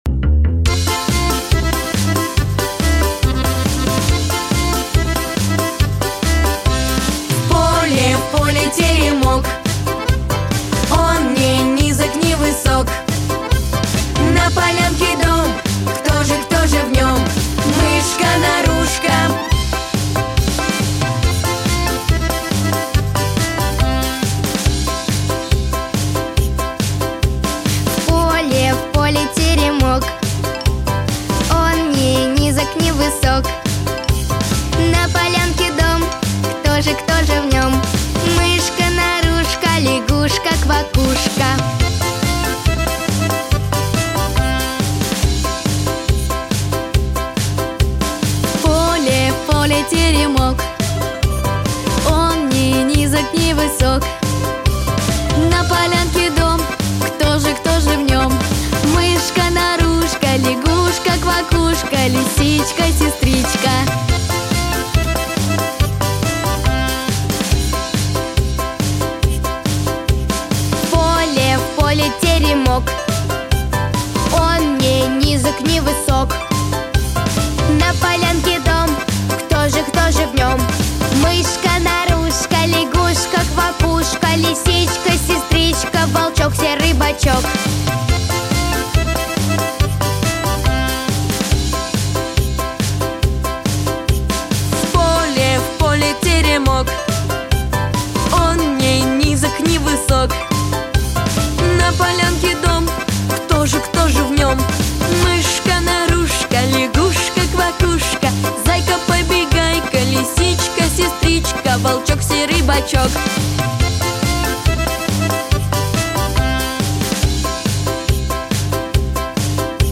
Песни из мультфильмов